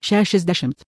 Va pridedu du lietuviškų balsų pavyzdžius.